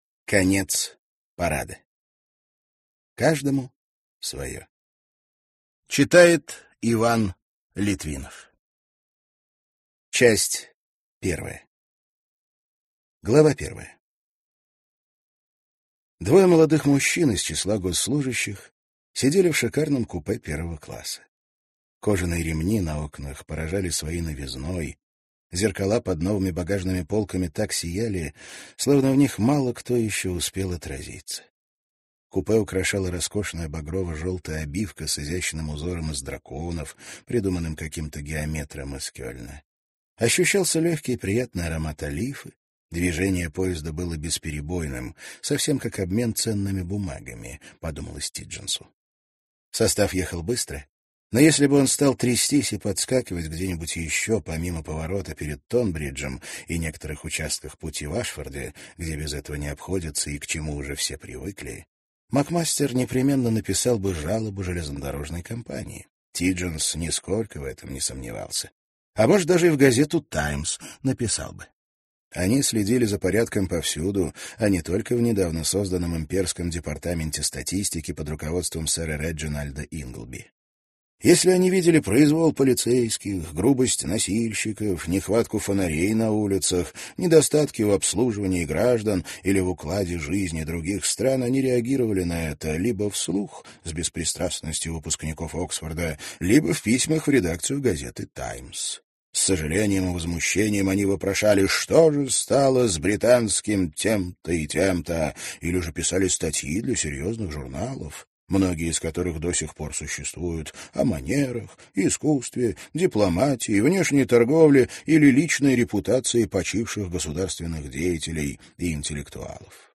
Аудиокнига Конец парада. Каждому свое | Библиотека аудиокниг